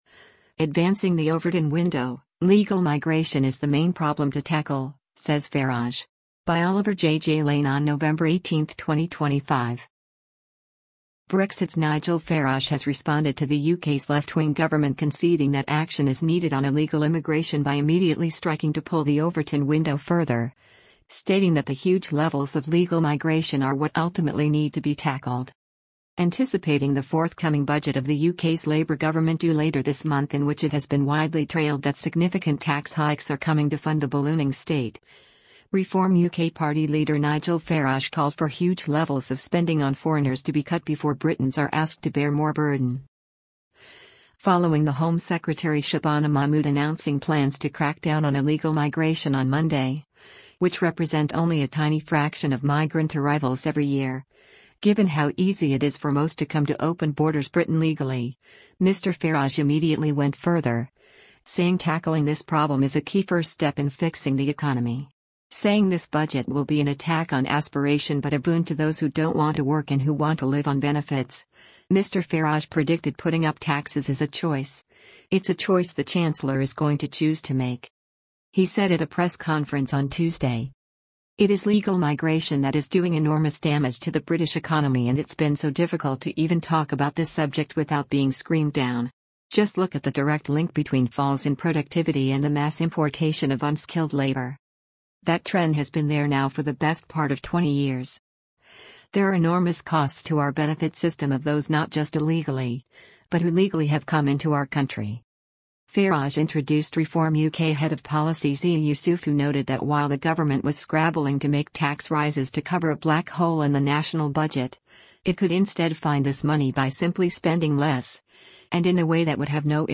Reform Party leader Nigel Farage speaks to the media following a Reform UK press conferenc
He said at a press conference on Tuesday: